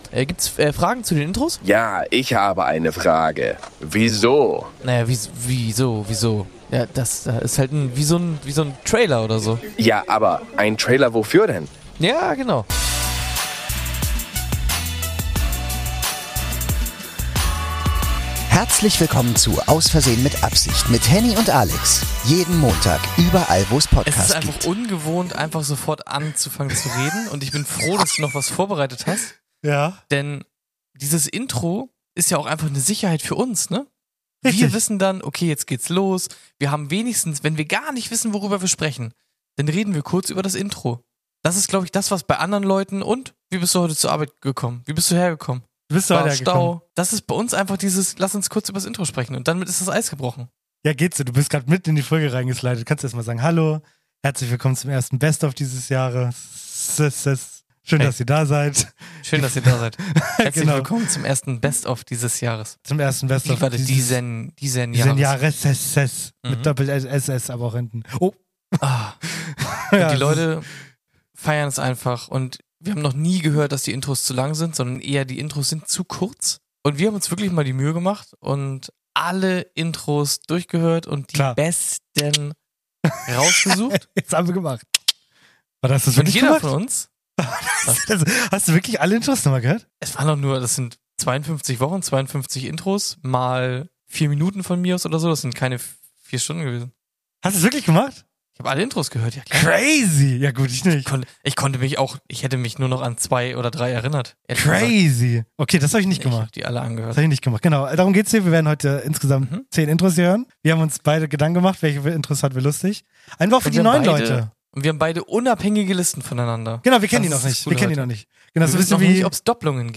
Ein kurzes Hörspiel zum genießen, und zwar jede Folge.